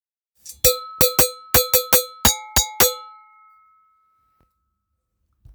ベル型の鉄製楽器ラベオです。ジュンジュンに装着しても使用できます。腰のあるサウンド、本格的に演奏するかたにも使える鉄楽器です。
素材： 鉄